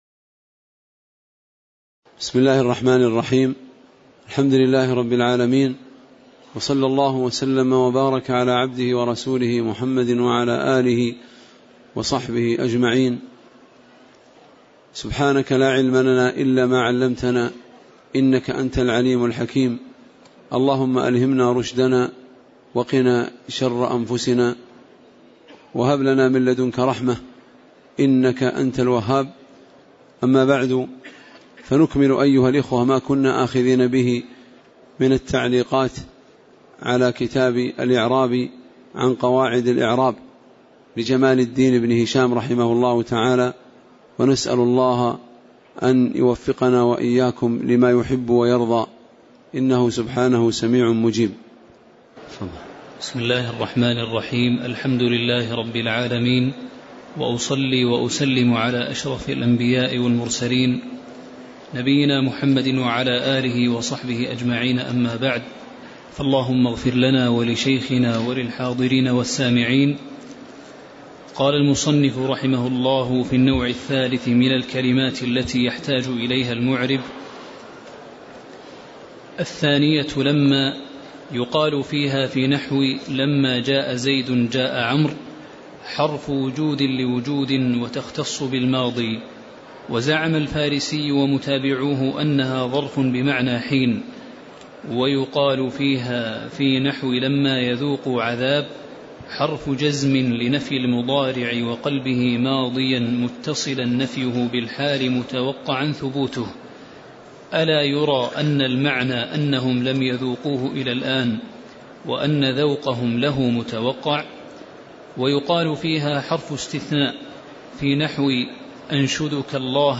تاريخ النشر ٩ شوال ١٤٣٨ هـ المكان: المسجد النبوي الشيخ